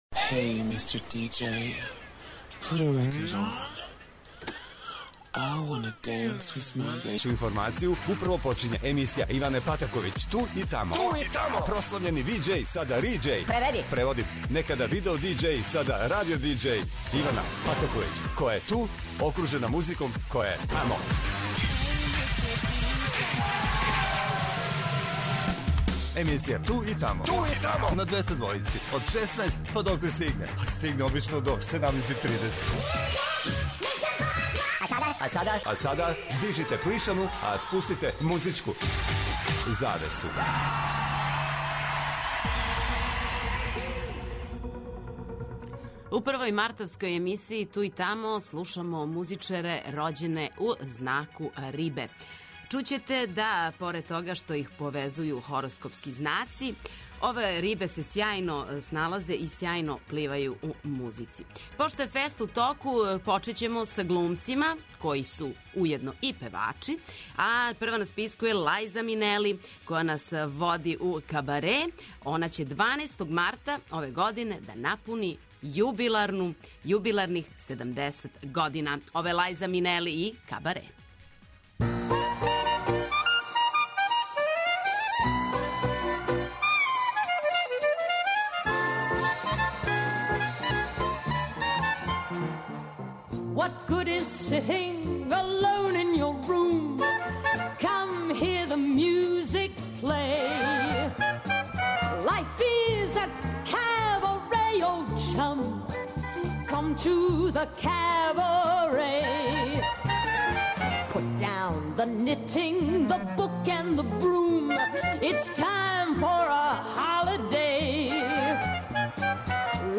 Као и сваке суботе слушаоци могу да се јаве и дају своје предлоге на задату тему.